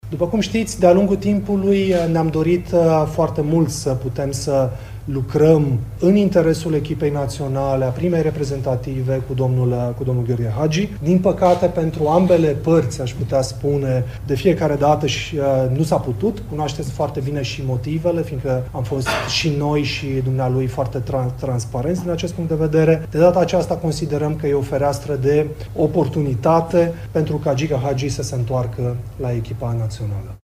Conferință de presă organizată ieri la Federația Română de Fotbal: se fac formalitățile privind numirea lui Gheorghe Hagi la cârma echipei naționale.
Președintele Federației, Răzvan Burleanu: „Este o fereastră de oportunitate pentru ca Gică Hagi să se întoarcă la echipa națională”